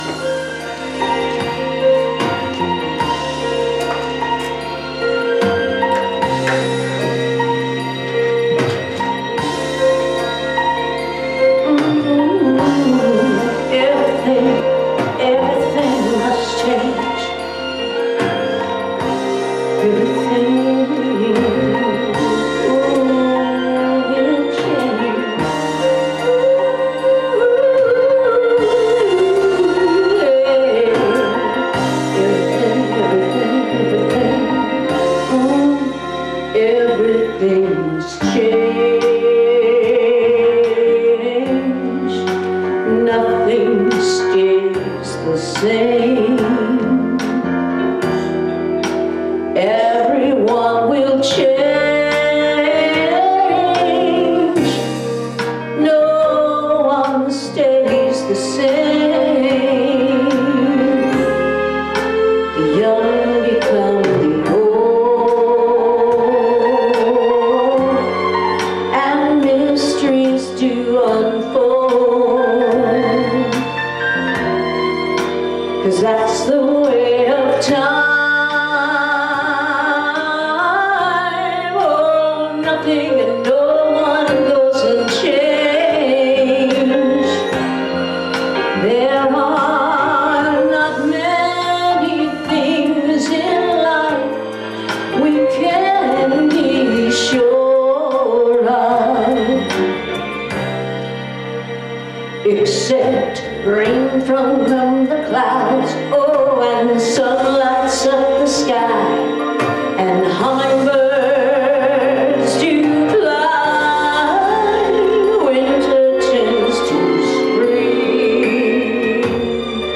Series: Sermons 2023